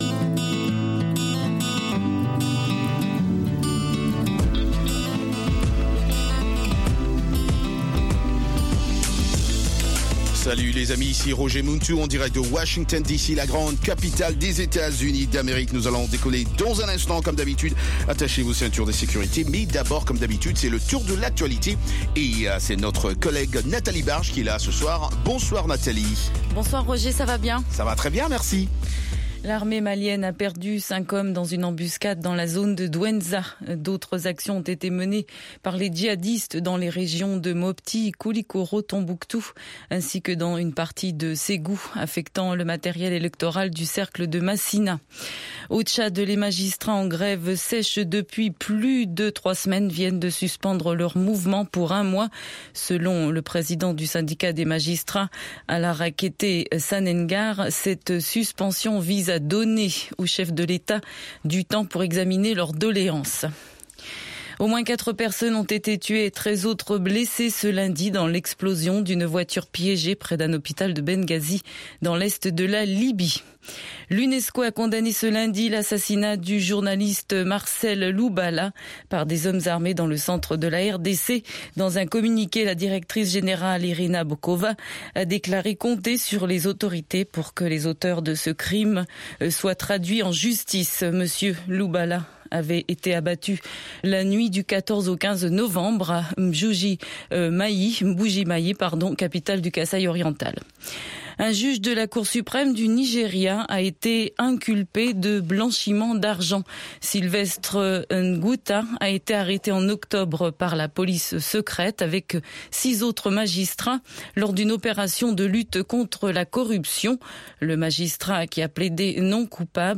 RM Show - Le hit-parade Americain